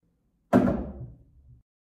derevo_stul.ogg